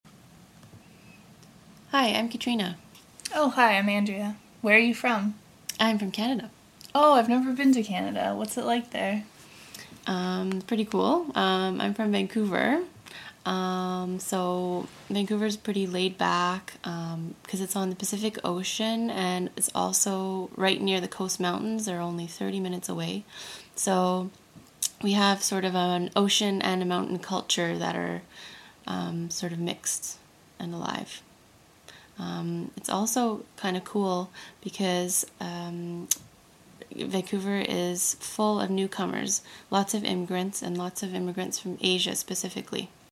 Américain / Canadien